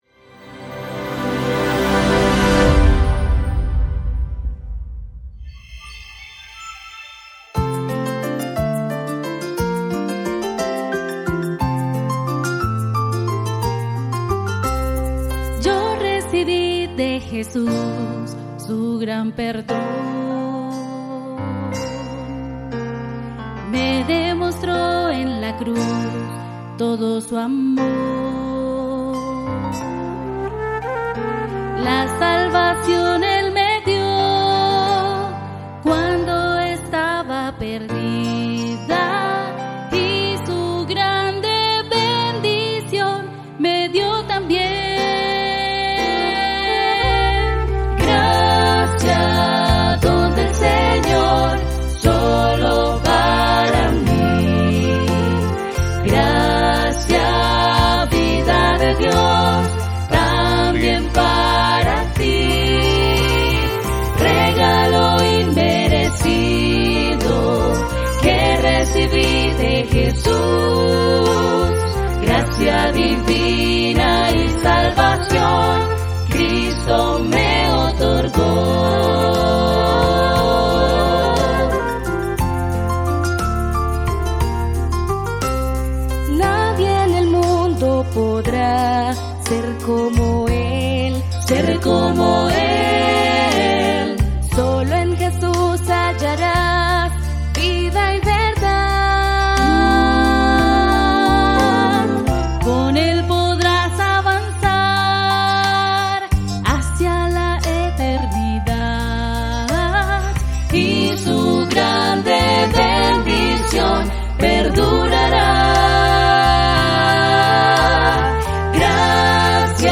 El grupo Sello de Gracia nos envuelve en una atmósfera de adoración con armonías impactantes y letras que elevan el alma. Descubre su historia y su misión musical.